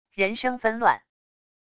人声纷乱wav音频